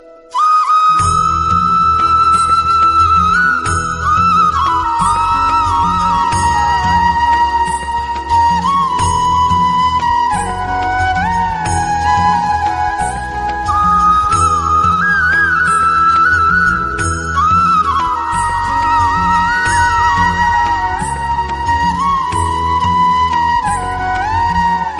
Singer : Instrumental